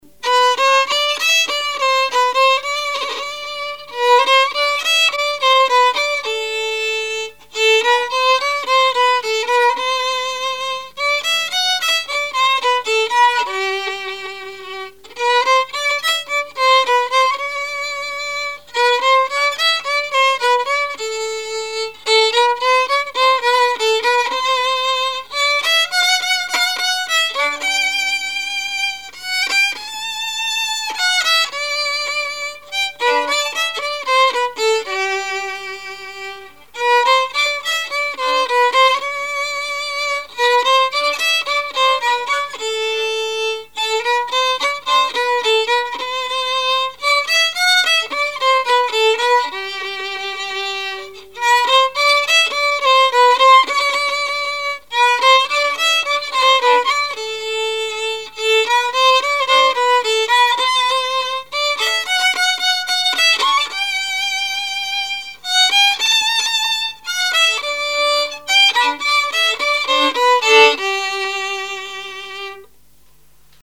musique varieté, musichall
danse : valse
Répertoire musical au violon
Pièce musicale inédite